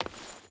Stone Chain Run 5.wav